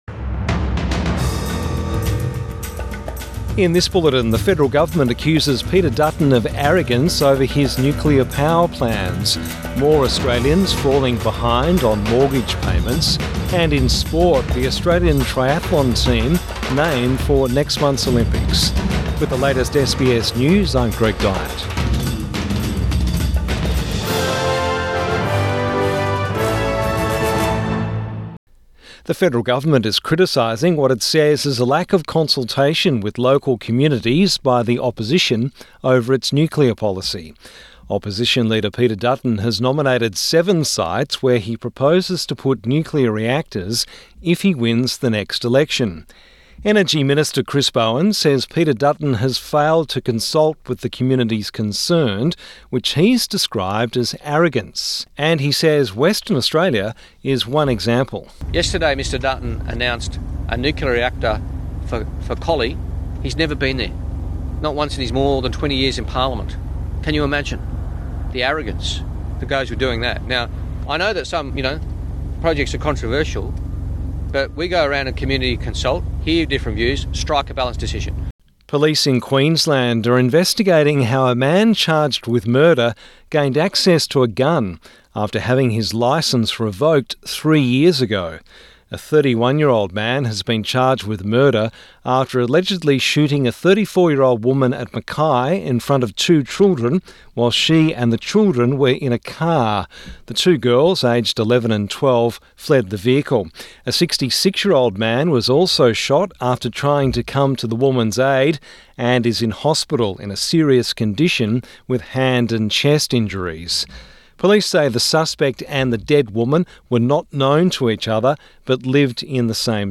Evening News Bulletin 20 June 2024